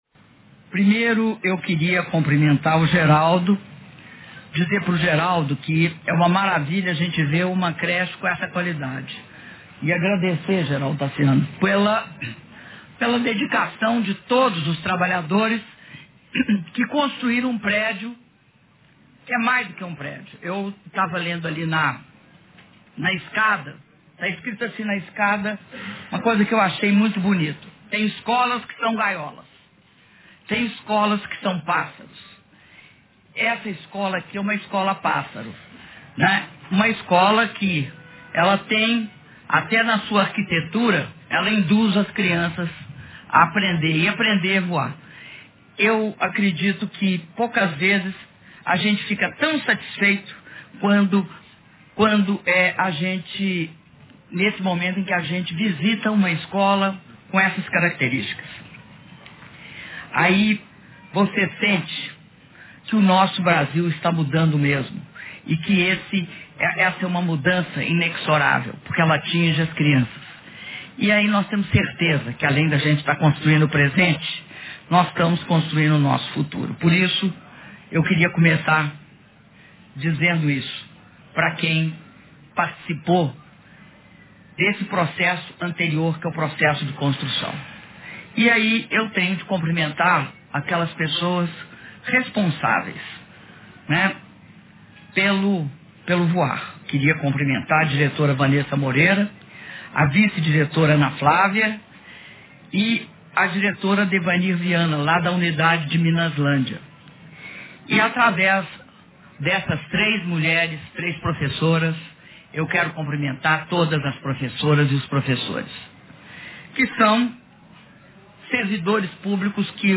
Discurso da Presidenta da República, Dilma Rousseff, durante cerimônia de inauguração das Unidades Municipais de Educação Infantil e contratação de 44 novas unidades - Belo Horizonte/MG